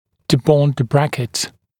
[dɪ’bɔnd ə ‘brækɪt][ди’бонд э ‘брэкит]снять брекет (букв. отклеить)